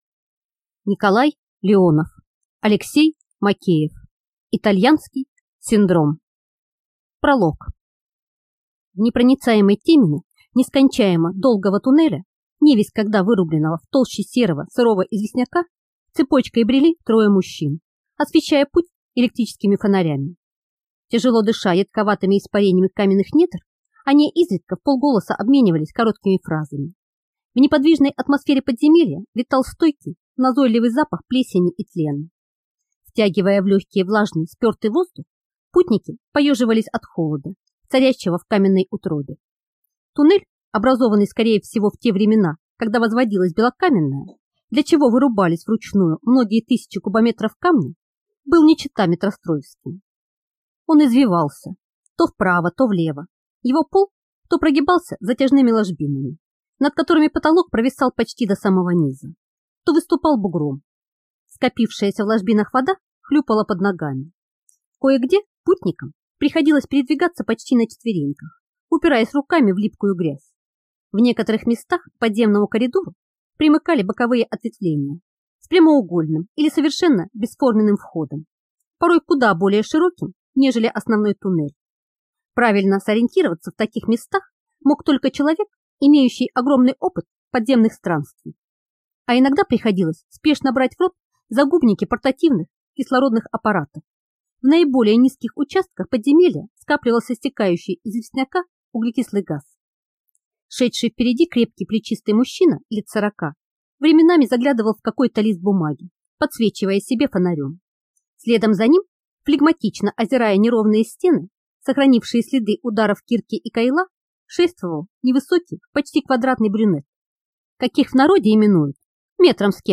Aудиокнига Итальянский синдром